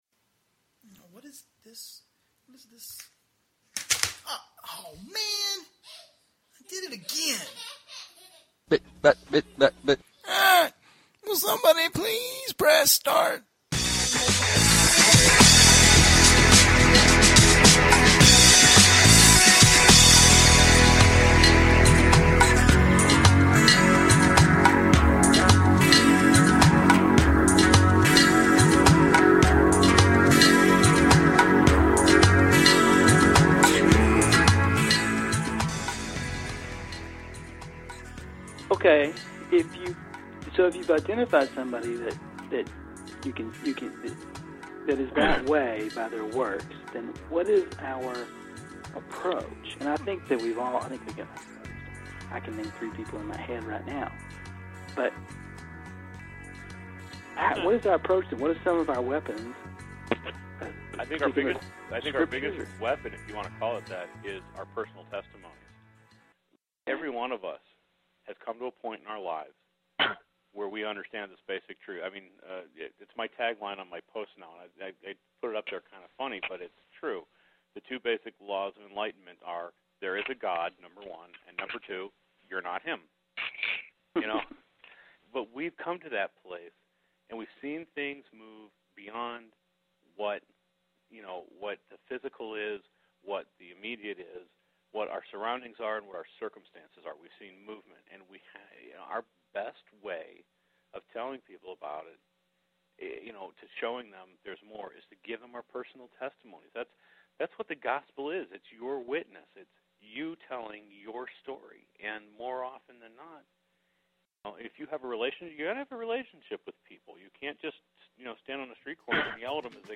Here is the second part of the call.